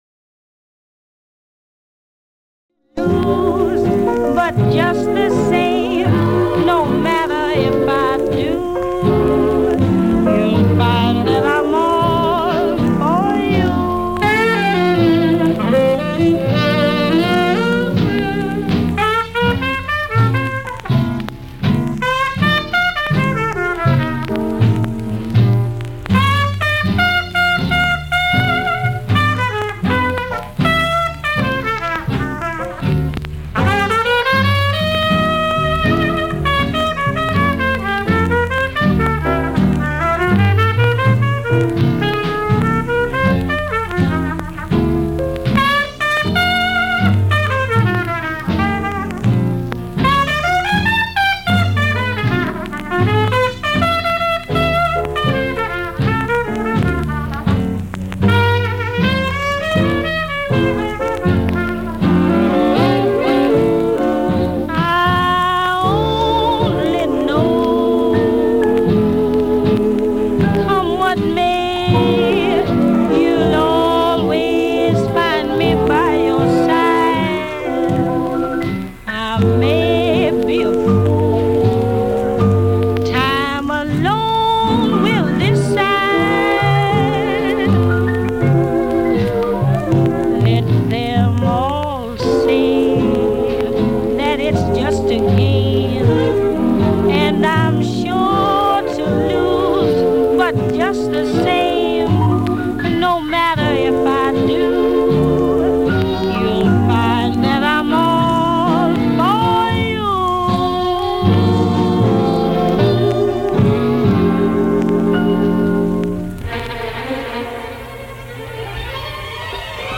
Audio Recording; Radio talk shows
Physical Format Audio cassette